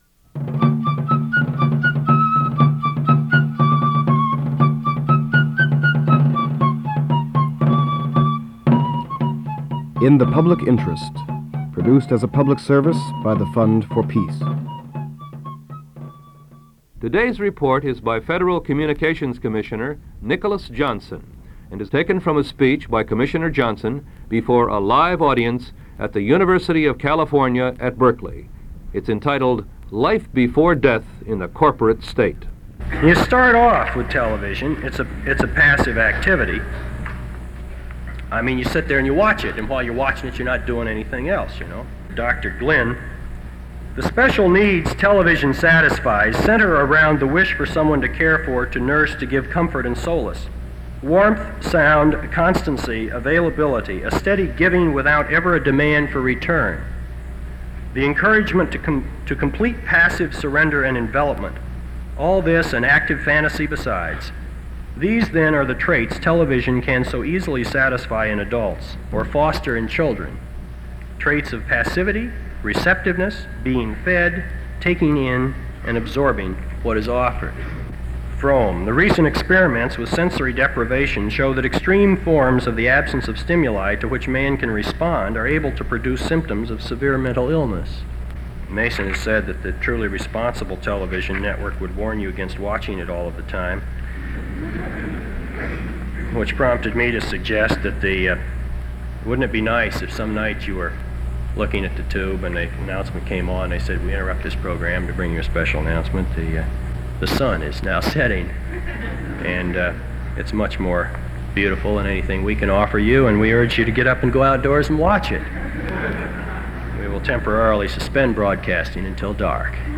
This is the first of two files in which “In the Public Interest” ran an excerpt from Nicholas Johnson’s speech, “Life Before Death in the Corporate State,” University of California, Berkeley, November 5, 1970.